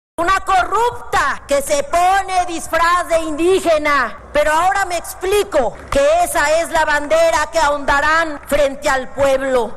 De inmediato, Morena y sus aliados abandonaron el salón del Pleno, y fue la diputada de Morenista, Aleida Alavez, la encargada de responder a la panista, y la calificó de corrupta.
Al tiempo que legisladores de Juntos Haremos Historia, le gritaban «fuera, fuera, fuera!.